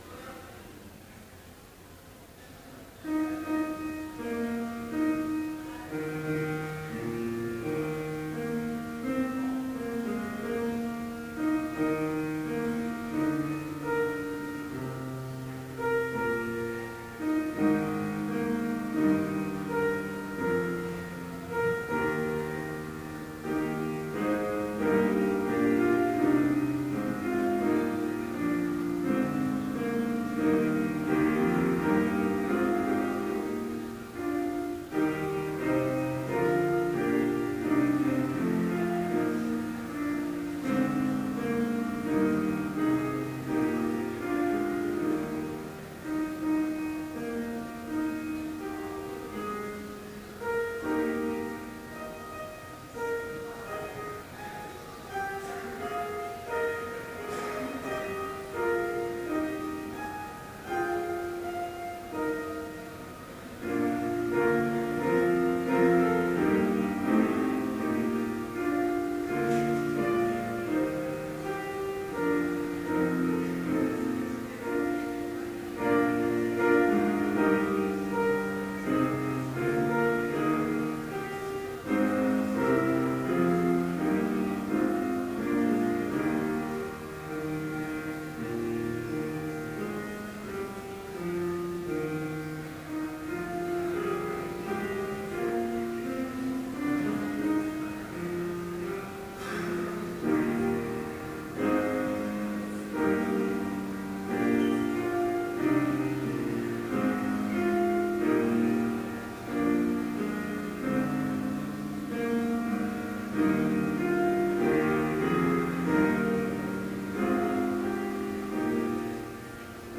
Complete service audio for Chapel - May 3, 2013